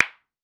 Foley Sports / Pool / Ball Impact Intense.wav
Ball Impact Intense.wav